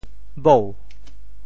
𡚸 部首拼音 部首 女 总笔划 6 部外笔划 3 普通话 yāo 潮州发音 潮州 bhou2 白 潮阳 bhou2 白 澄海 bhou2 白 揭阳 bhou2 白 饶平 bhou2 白 汕头 bhou2 白 中文解释 潮州 bhou2 白 对应普通话: yāo 潮州话方言字。